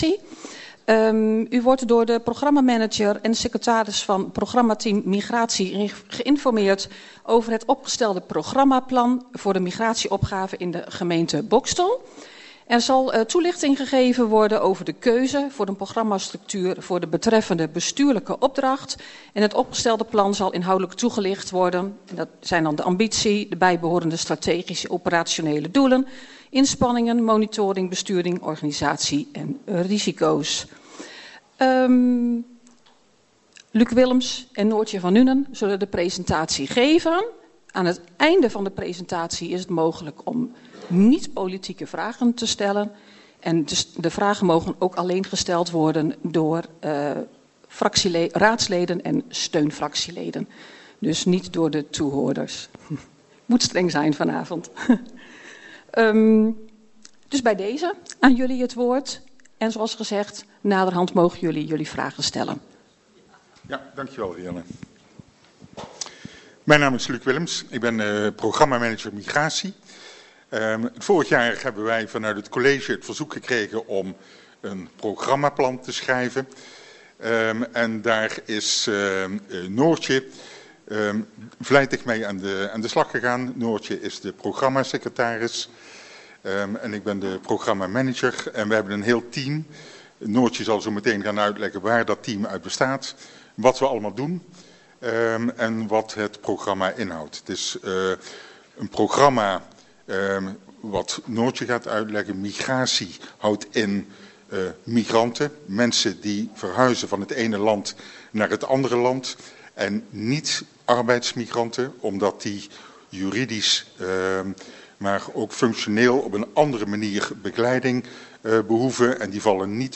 Agenda MijnGemeenteDichtbij - Informatie-/uitwisselingsbijeenkomst raad Boxtel dinsdag 18 november 2025 19:30 - 21:45 - iBabs Publieksportaal
Locatie Raadzaal Boxtel Voorzitter Rianne van Esch Toelichting Informatie-/uitwisselingsavond Programmaplan Migratie + Economische Visie 2030 Agenda documenten Geluidsopname informatie-uitwisselingbijeenkomst 61 MB